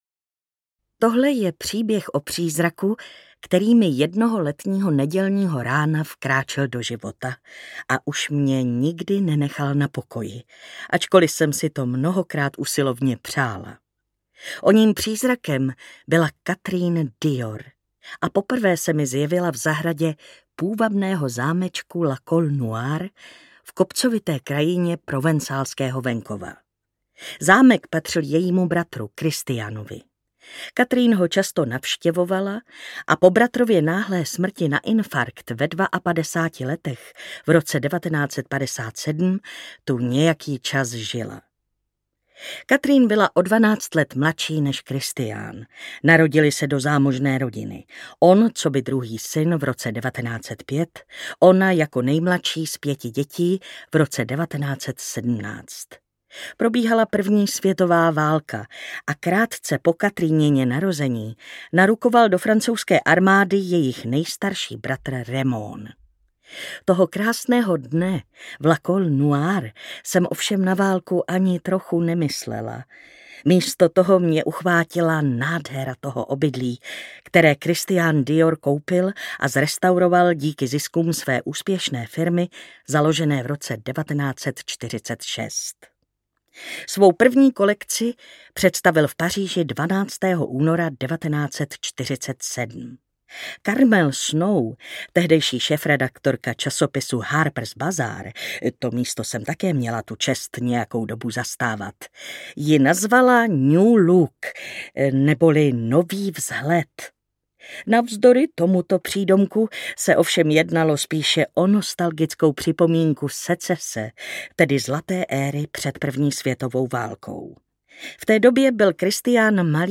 Miss Dior audiokniha
Ukázka z knihy
• InterpretMartina Hudečková